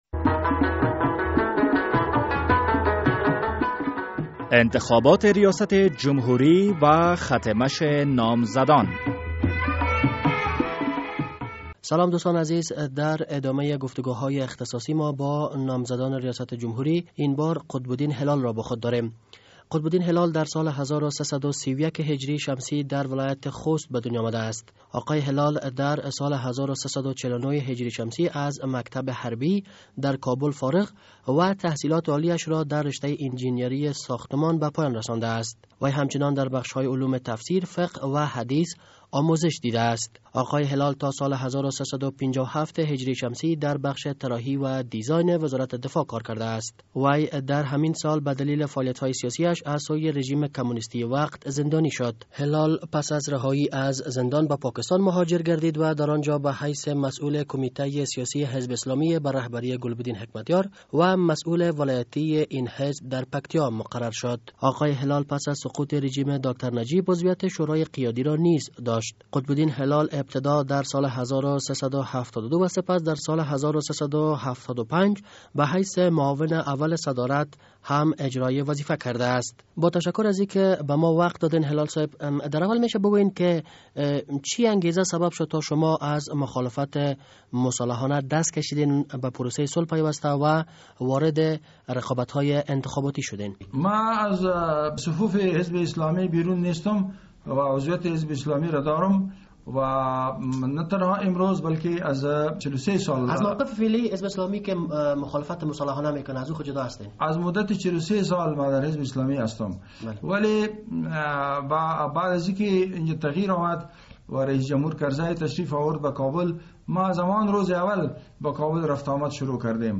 مصاحبهء اختصاصی با قطب الدین هلال